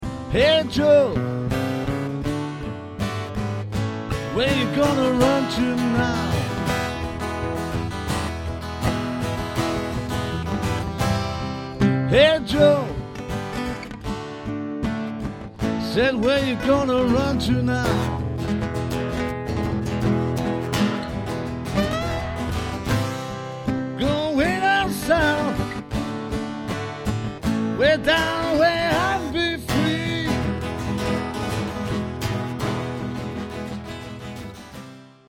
Ashington Folk Club - Spotlight 16 November 2006